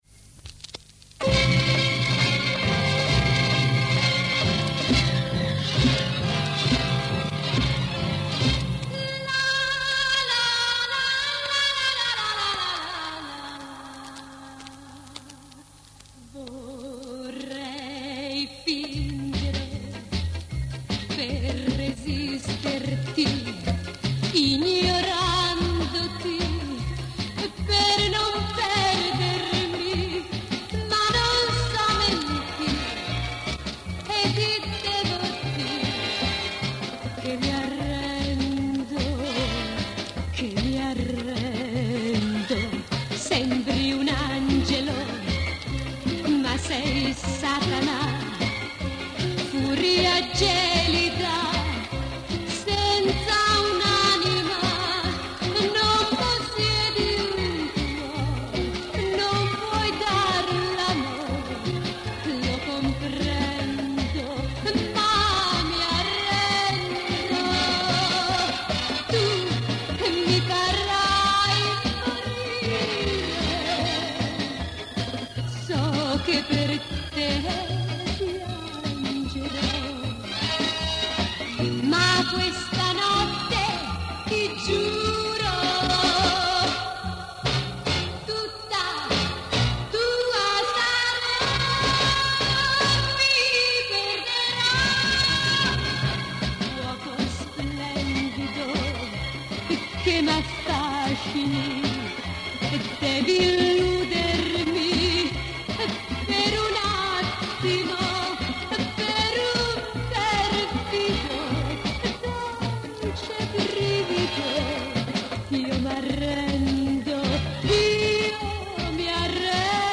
танцевальных версий в стиле твиста